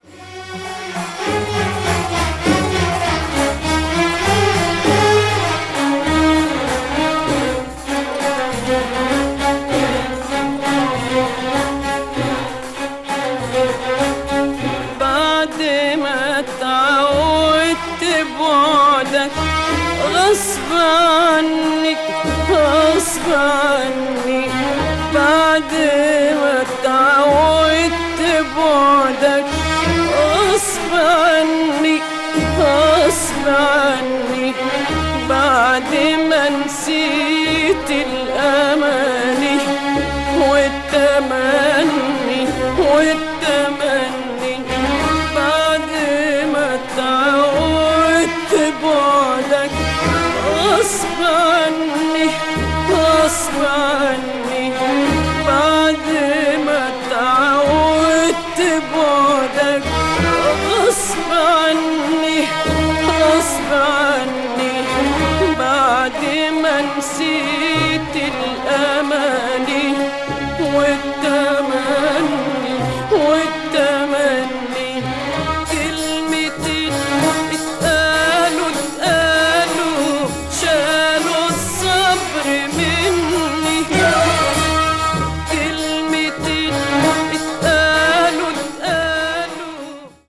media : EX-/EX-(薄いスリキズによるわずかなチリノイズが入る箇所あり)
arab   egypt   oritental   traditonal   world music